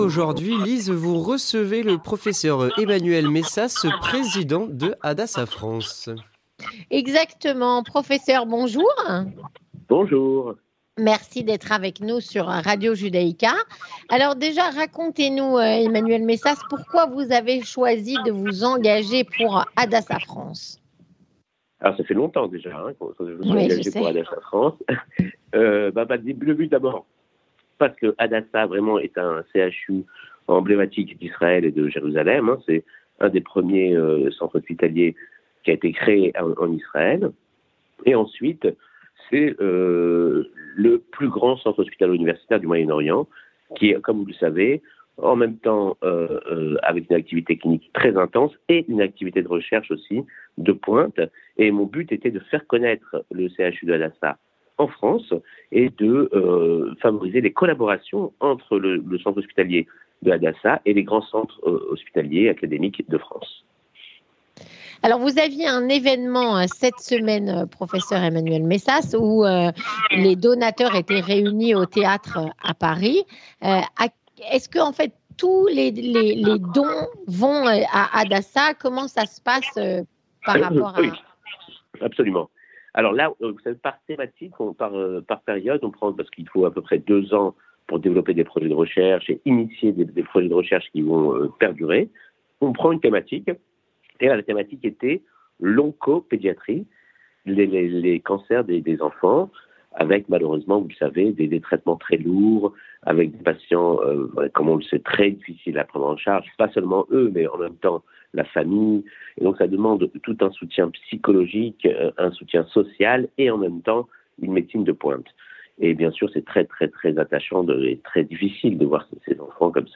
L'interview communautaire